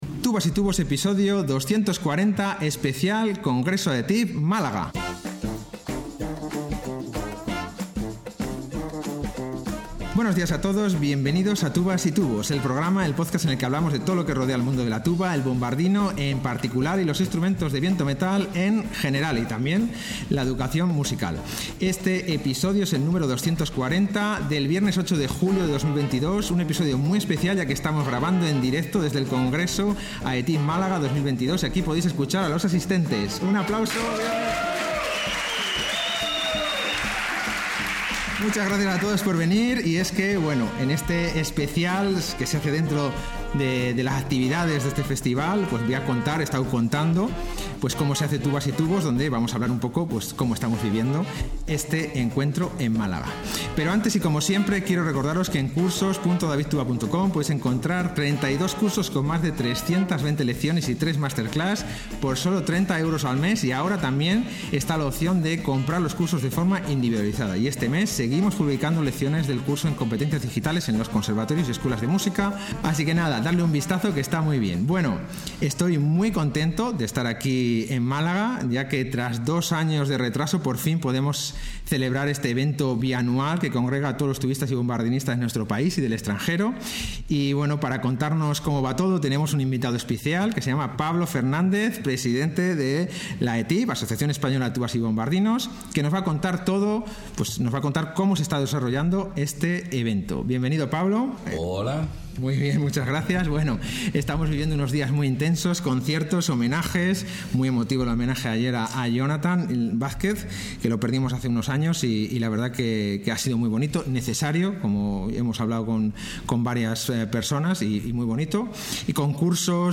Especial grabado en directo desde el Congreso AETYB Málaga 2022